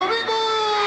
abertura-domingo-legal-com-gugu-liberato-2000-mp3cut-audiotrimmer.mp3